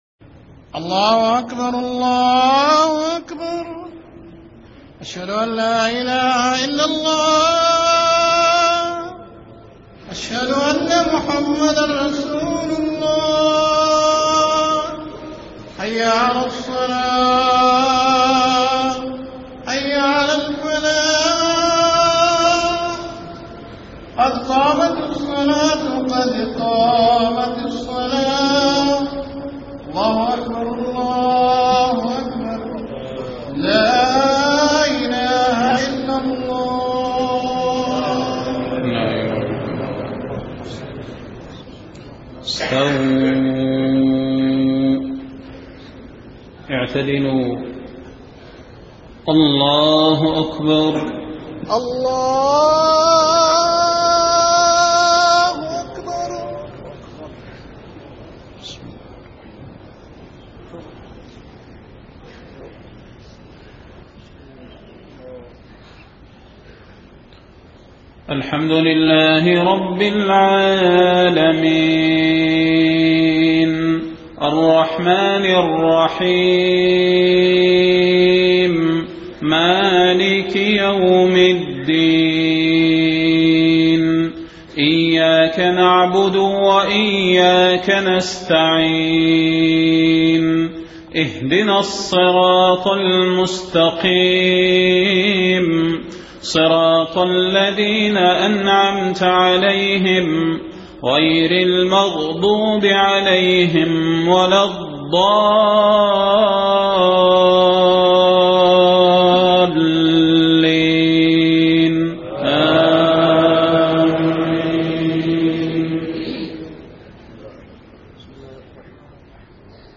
صلاة المغرب 23 محرم 1430هـ سورتي الفيل وقريش > 1430 🕌 > الفروض - تلاوات الحرمين